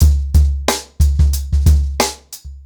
TrackBack-90BPM.61.wav